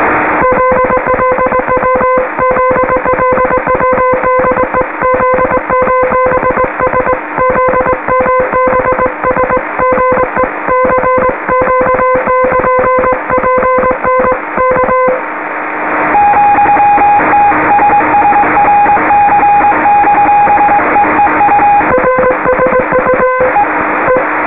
Some historic sounds mainly of New Zealand marine radiotelegraphy from 1993.
All the recordings were done while the ARAHURA was in the Cook Strait / Wellington / Picton area. Those featuring the ARAHURA sound noisy due to the key clicks caused by the receivers being demuted during key down periods of the transmitter.